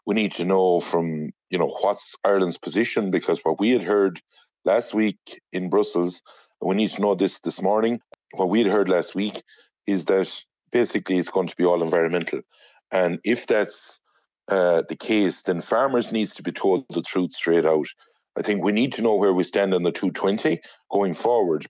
Member of the Oireachtas Agriculture Committee Michael Fitzmaurice says Minister McConalogue will also be asked the implications of the new CAP deal for Irish farmers: